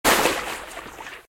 splash1.ogg